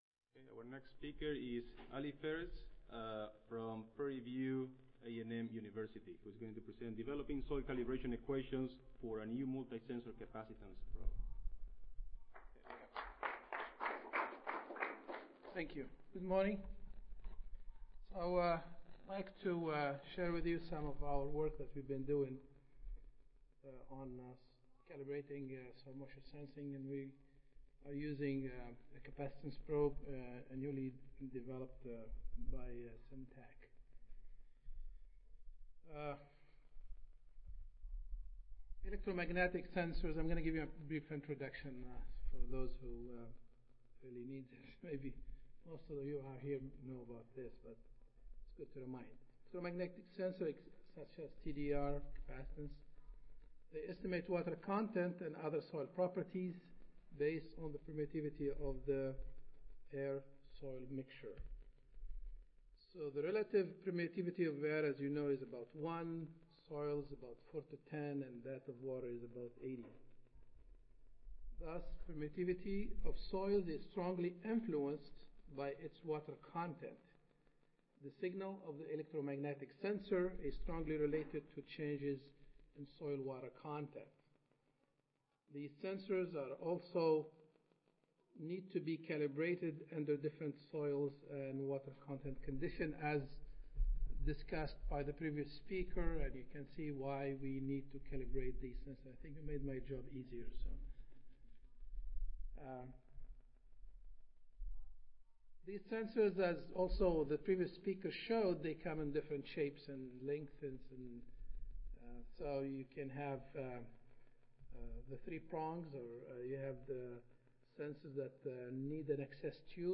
Prairie View A&M University Audio File Recorded Presentation